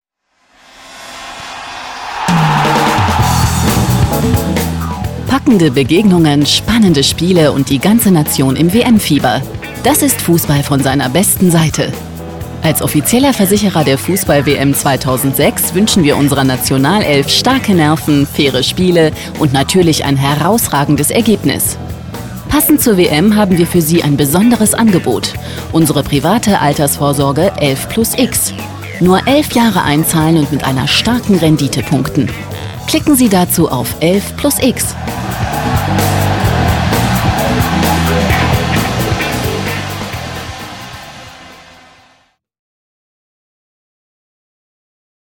Sprechprobe: Sonstiges (Muttersprache):
female voice over talent german for commercials, tv, radio, synchron, dubbing, audio-books, documentaries, e-learning, podcast